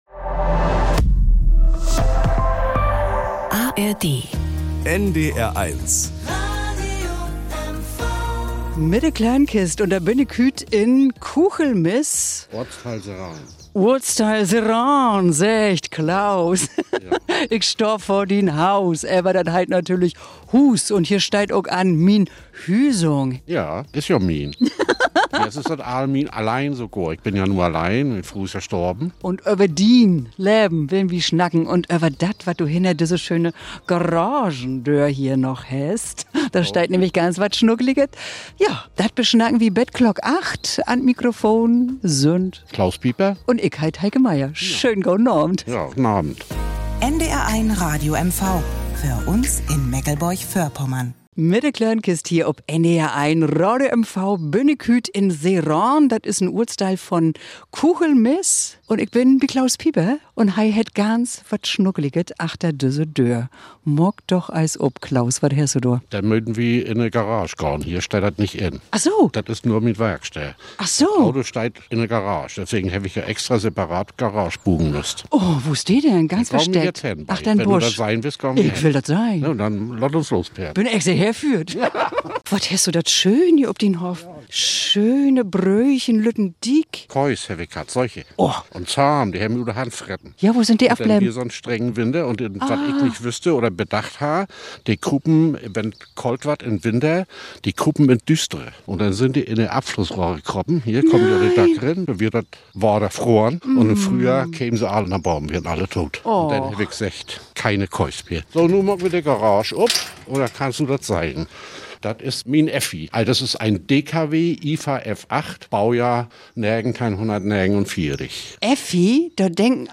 Plattdeutsches aus MV Podcast